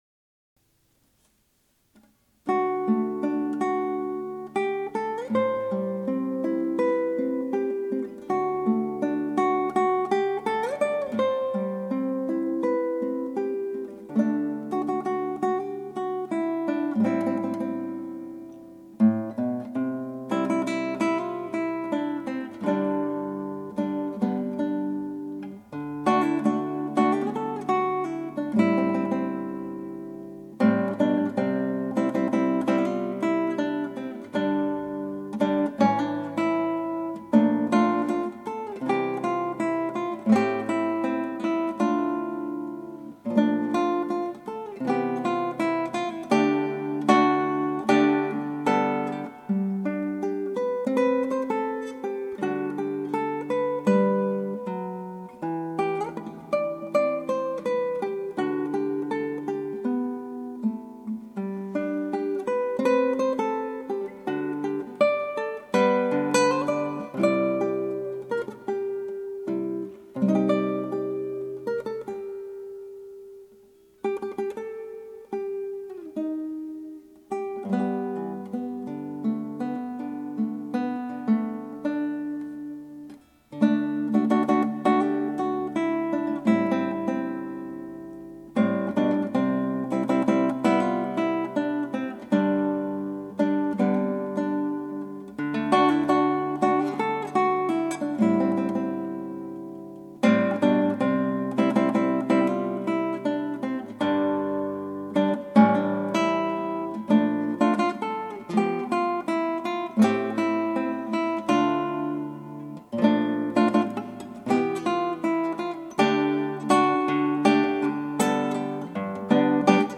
クラシックギター　ストリーミング　コンサート